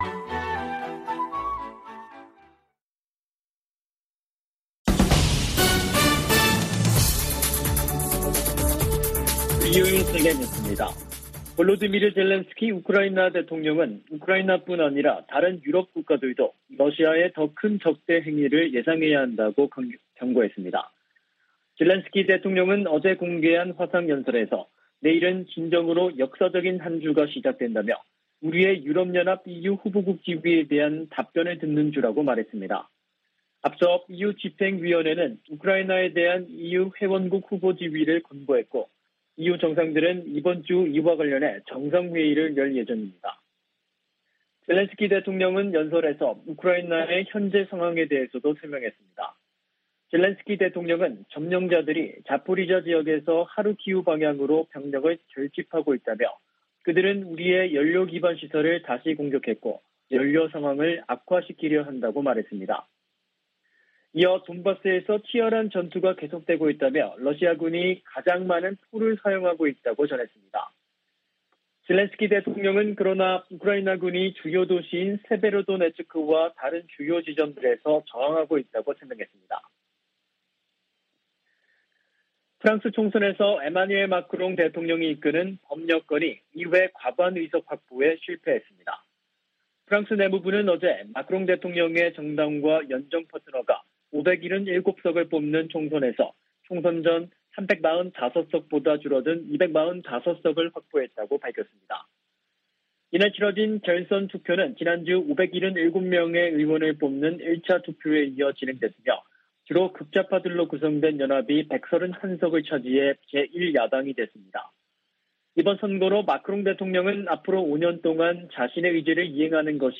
VOA 한국어 간판 뉴스 프로그램 '뉴스 투데이', 2022년 6월 20일 3부 방송입니다. 미국의 핵 비확산 담당 고위 관리가 오는 8월 NPT 재검토 회의에서 북한 문제를 다룰 것을 요구했습니다. 북한의 7차 핵실험에 관해, 정치적 효과를 극대화하는 데 시간이 걸릴 수 있다는 관측이 나오고 있습니다. 유엔의 의사결정 구조 한계 때문에 북한의 행동을 바꾸기 위한 국제적 단합에 제동이 걸릴 것으로 미 의회조사국이 진단했습니다.